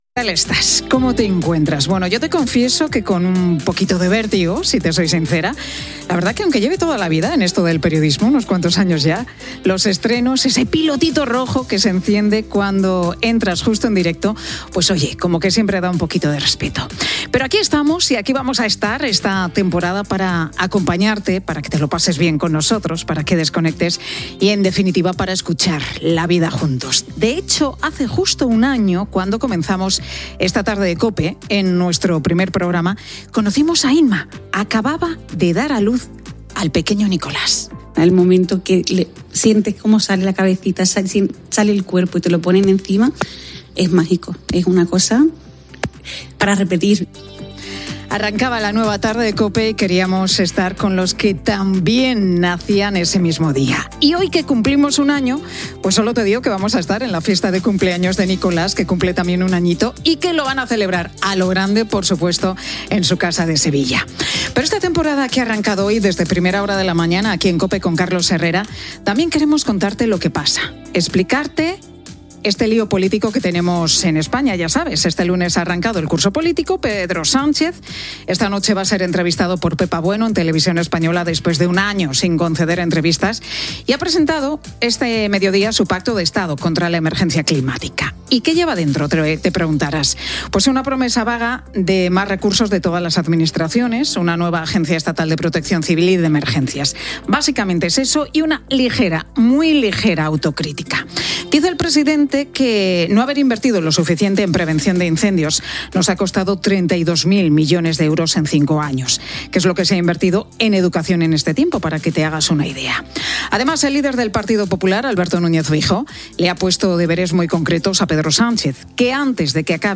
Paraules de la mare d'un nen que compleix un any, que va nèixer el dia que començava aquesta etapa de "La tarde". Comentaris sobre les notícies del dia.
Entreteniment
FM